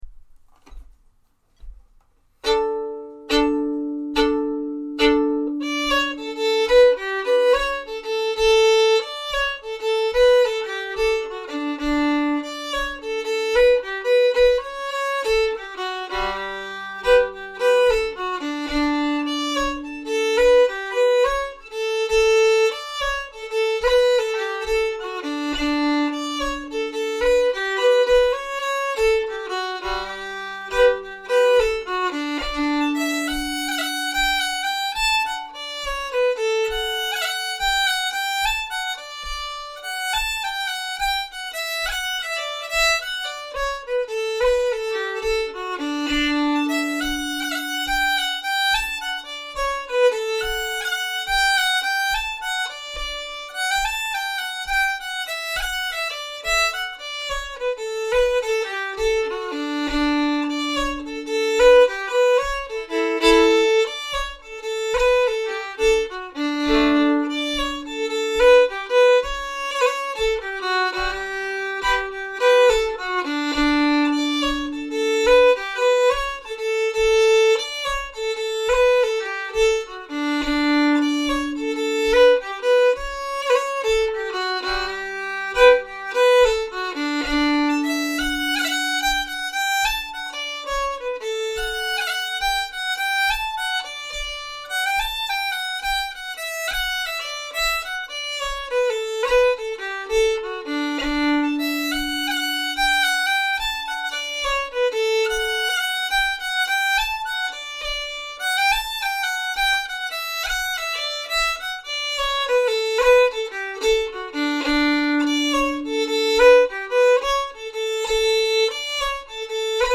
Black Rogue, slower
Black-Rogue-slower.mp3